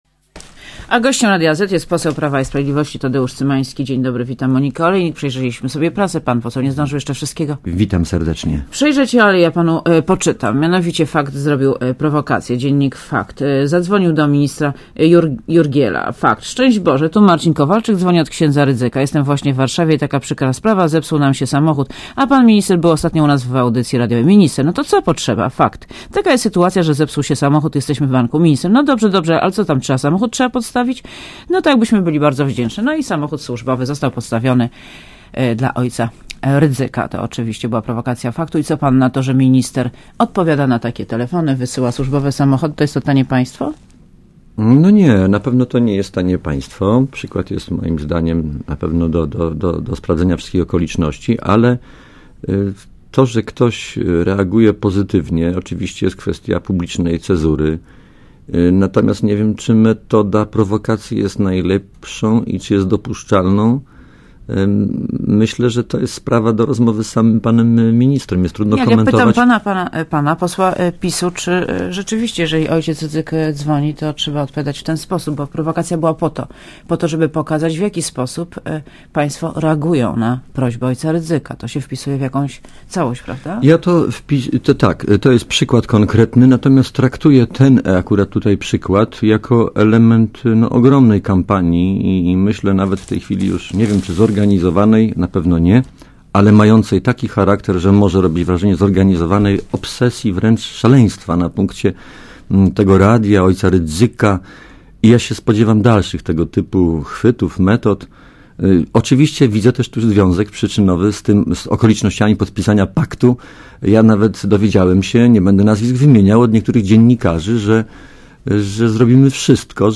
Posłuchaj Wywiadu Przysłanie służbowego ministerialnego samochodu ojcu Tadeuszowi Rydzykowi na pewno jest naganne - powiedział Cymański.